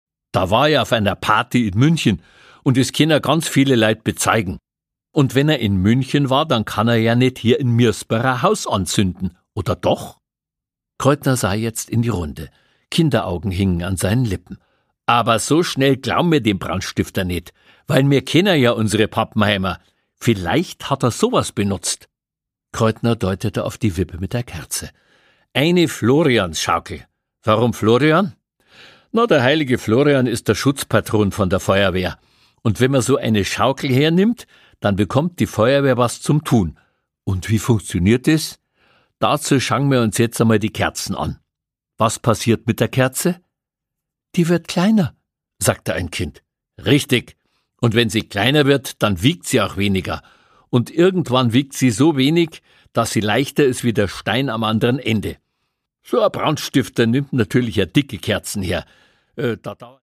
Produkttyp: Hörbuch-Download
auf unnachahmliche Weise bajuwarisch.